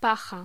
Locución: Paja
voz